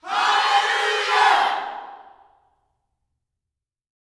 ALLELUJAH1.wav